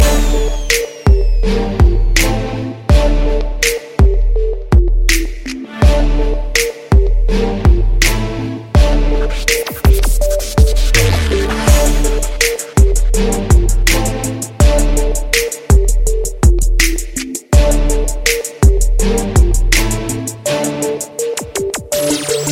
Dance - Electronics